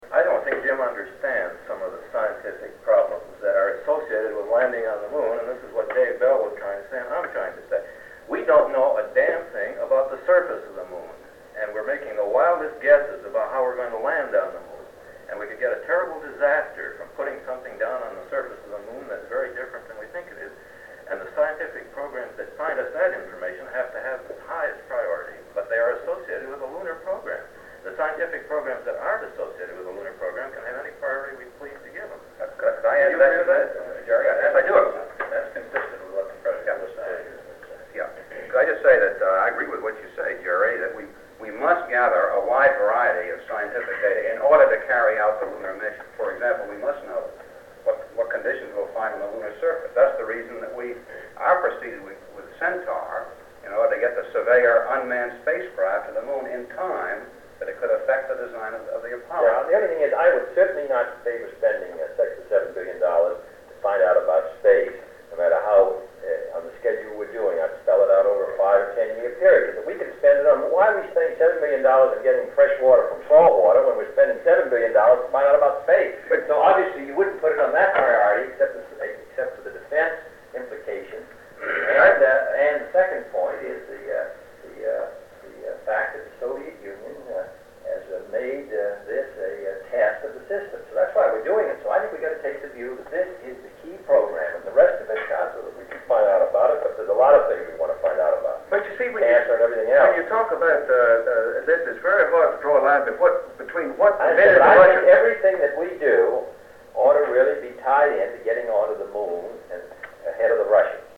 Tags: White House tapes Presidents Secret recordings Nixon tapes White house